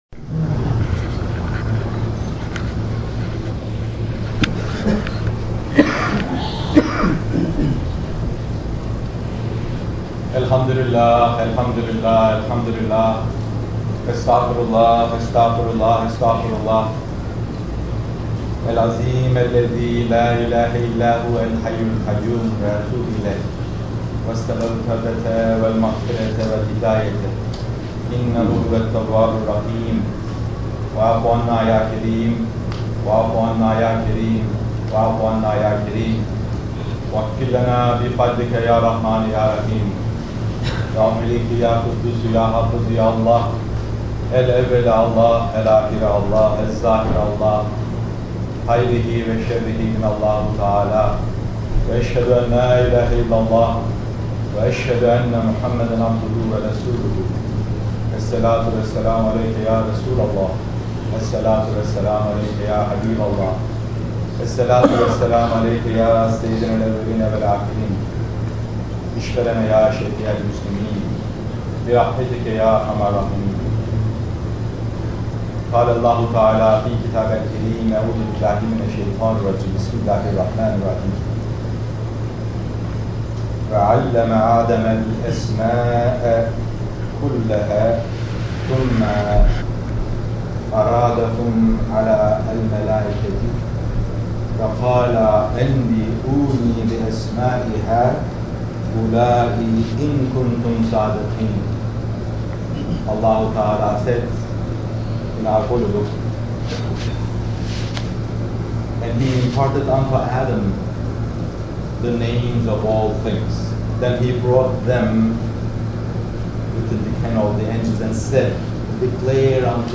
Jum`ah Khutba